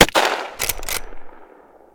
PNRifleSound.wav